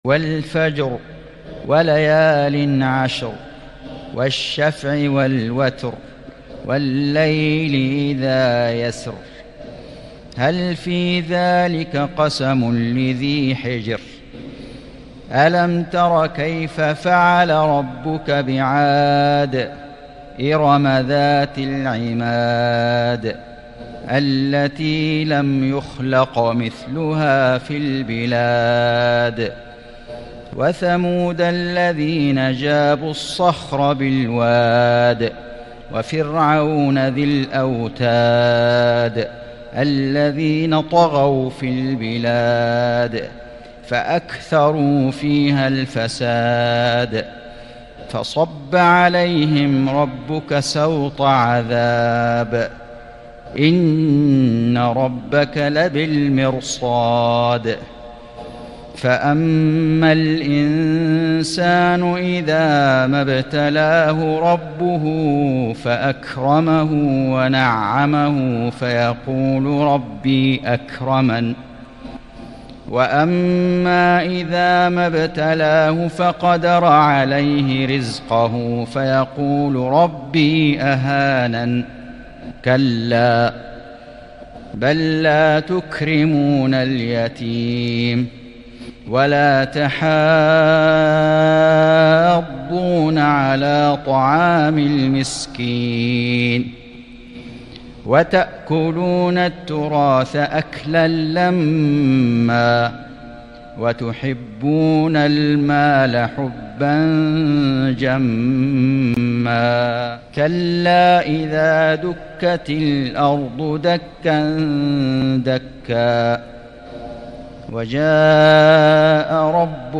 سورة الفجر > السور المكتملة للشيخ فيصل غزاوي من الحرم المكي 🕋 > السور المكتملة 🕋 > المزيد - تلاوات الحرمين